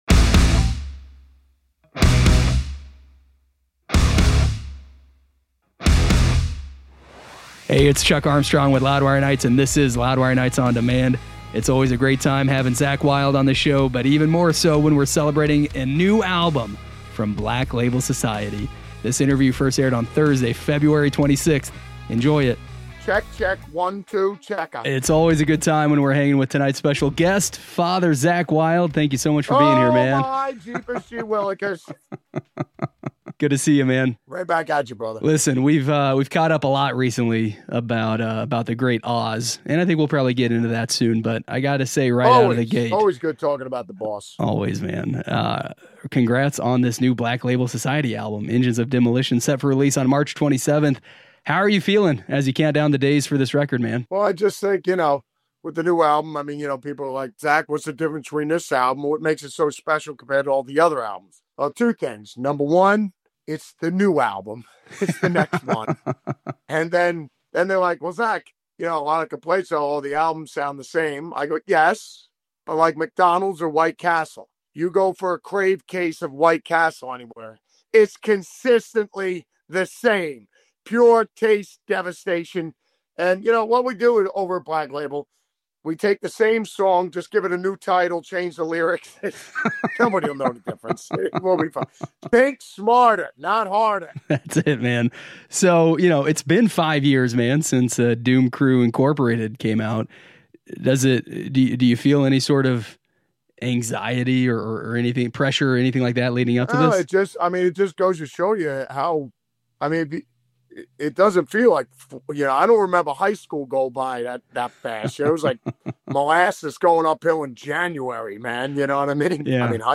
Zakk Wylde Interview - Ozzy's Inspiration, Pantera's Legacy + Black Label Society's New Album